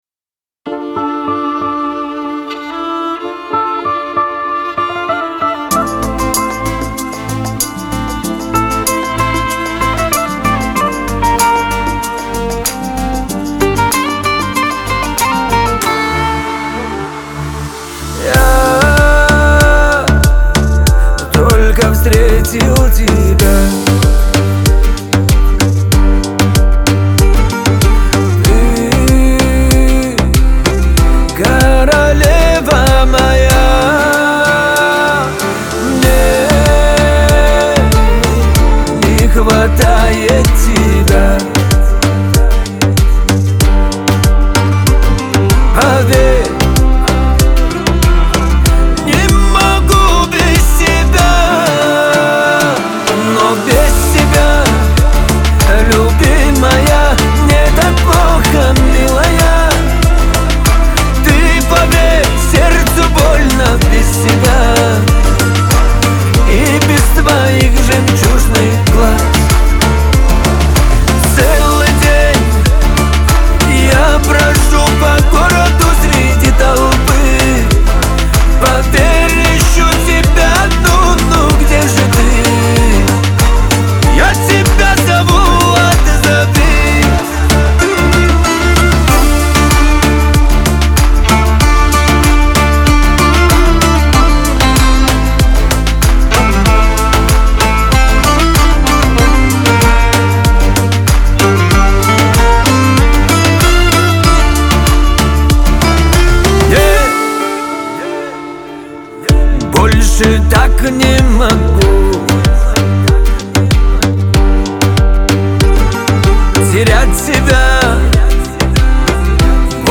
Кавказ – поп , Лирика , эстрада